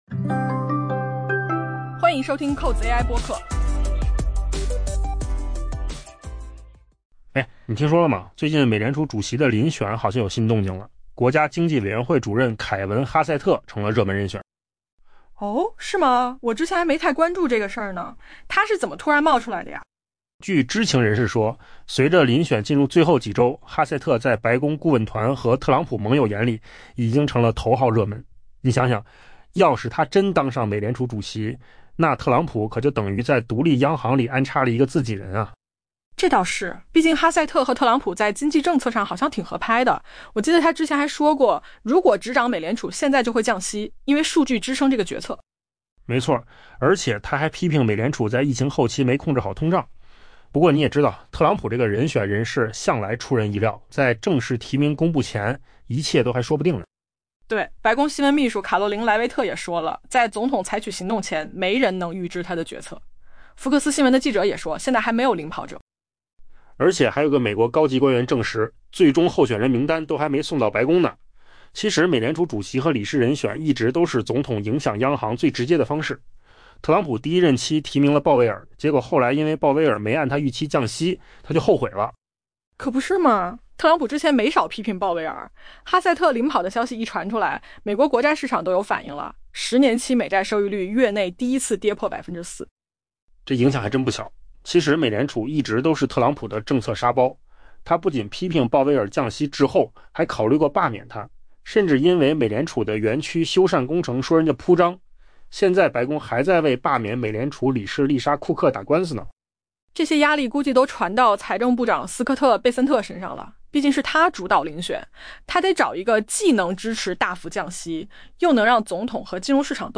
【文章来源：金十数据】AI播客：换个方